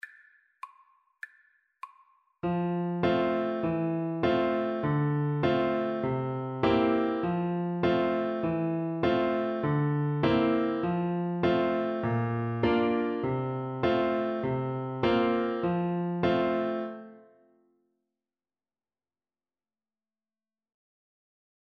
French Horn version
Playfully =c.100
2/4 (View more 2/4 Music)
F4-F5